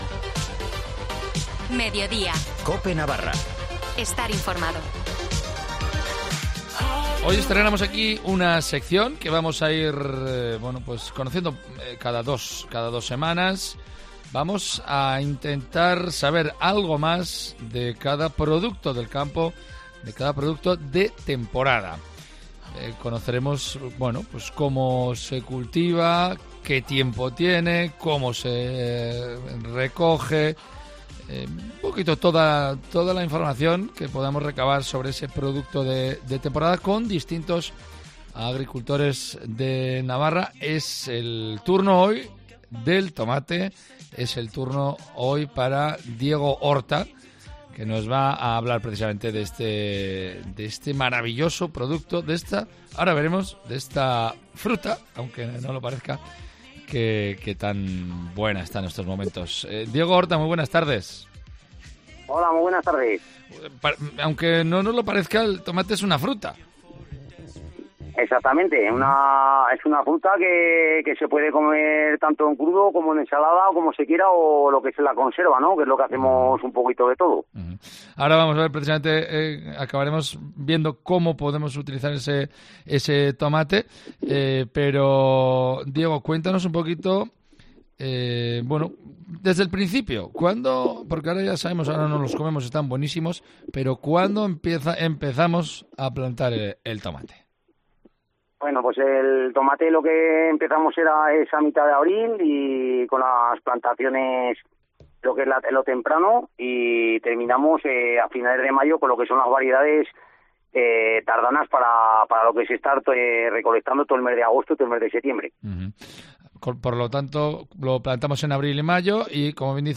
agricultor navarro